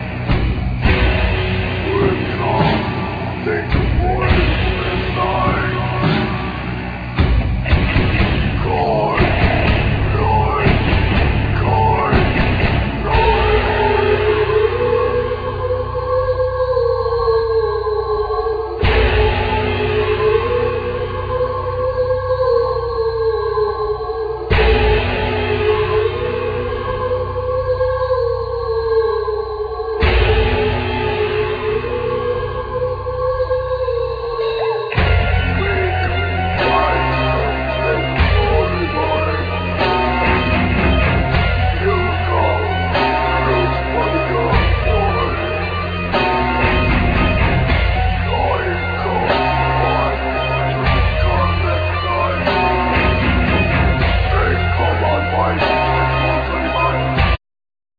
Guitar,Mandola,Voices
Drums,Percussion,Voices
Bass,Zurna,Saz,Flutes,Vocals
Text Performance
Cello